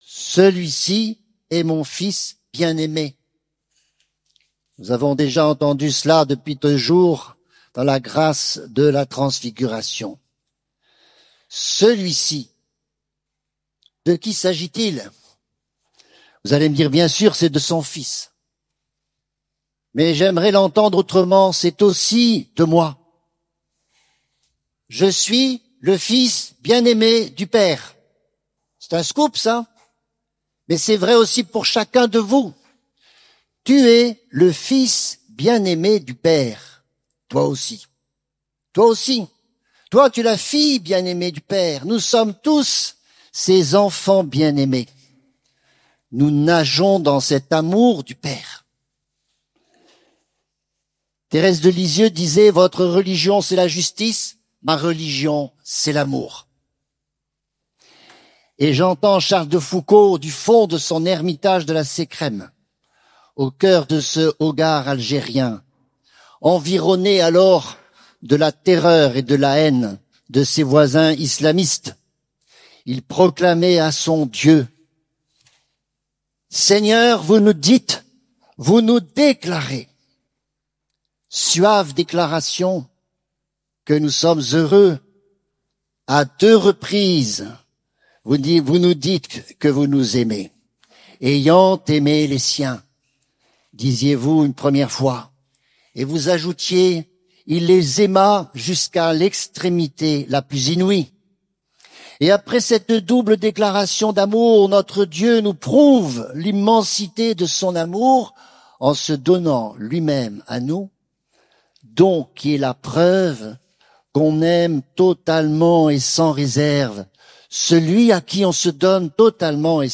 Nouan-le-Fuzelier, Festival des familles